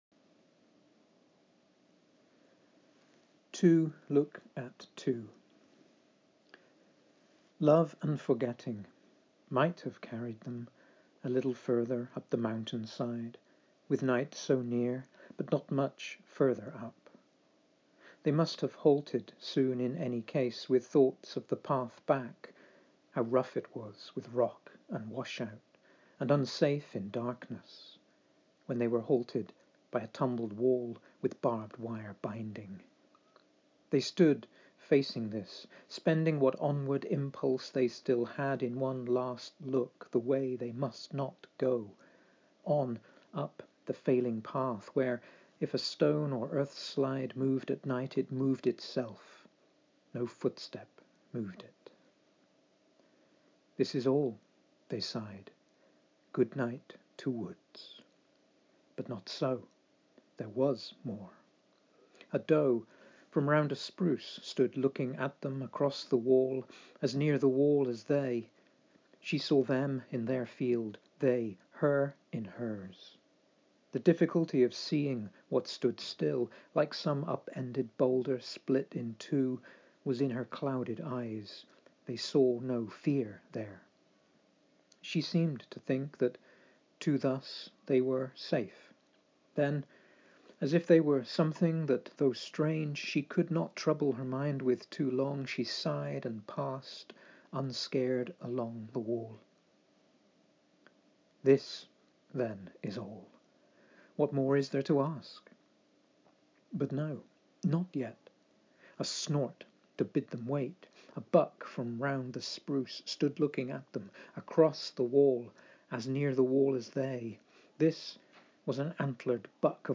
I read the poem here: